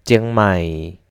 چیانگ مائی (Chiang Mai) (تلفظ: /ˈjɑːŋˈm/، from (تائی لو: เชียงใหม่)  [tɕʰiəŋ màj] ( سنیے)، (شمالیتھائی: ᨩ᩠ᨿᨦᩉ᩠ᨾᩲ᩵) [t͡ɕīaŋ.màj] (